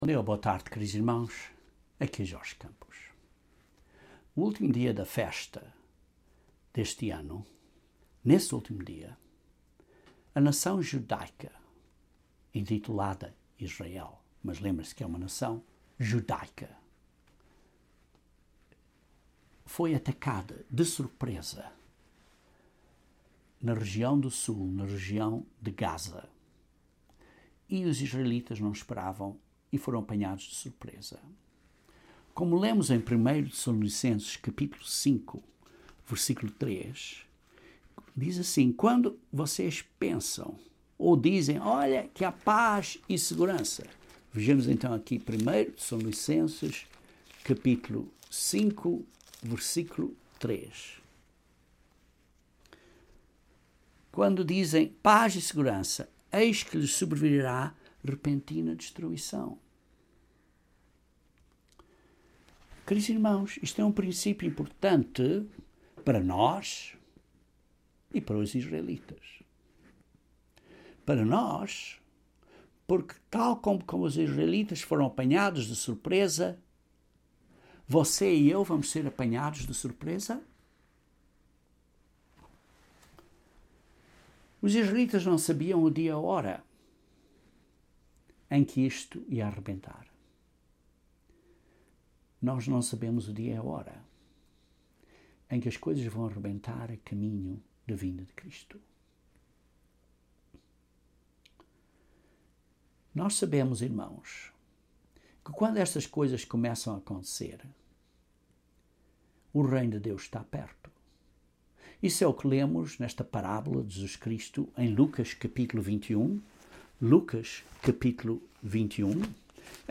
Os últimos ataques a Israel estão causando várias perguntas aos Cristãos. Este sermão aborda várias profecias do tempo do fim e menciona o que devemos fazer como Cristãos.